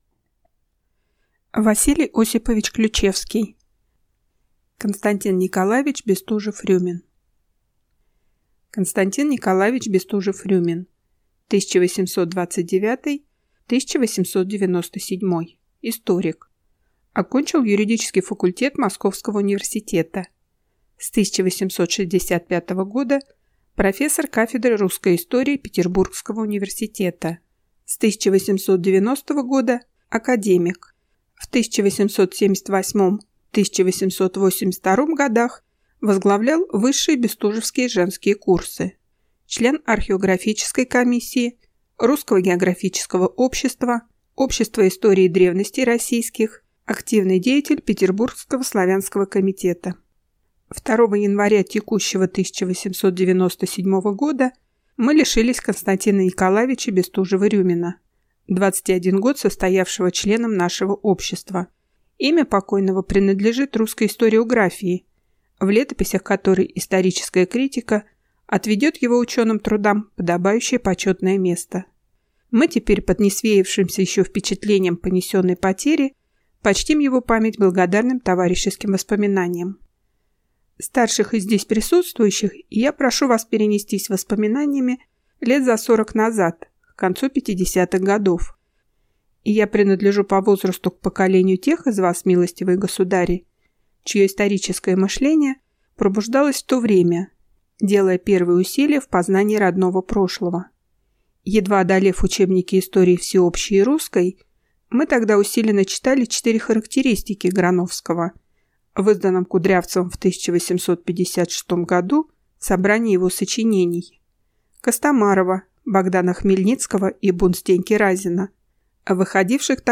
Аудиокнига К.Н. Бестужев-Рюмин | Библиотека аудиокниг